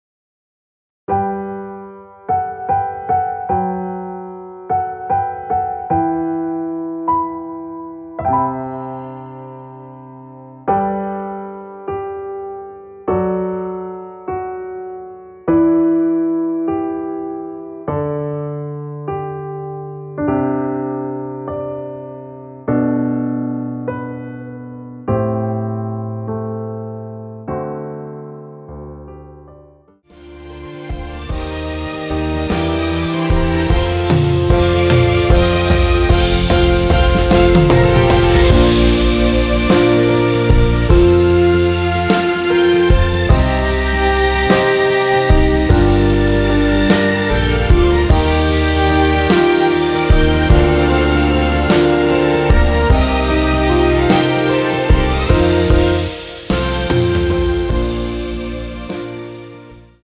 원키에서(+2)올린 MR입니다.
앞부분30초, 뒷부분30초씩 편집해서 올려 드리고 있습니다.
중간에 음이 끈어지고 다시 나오는 이유는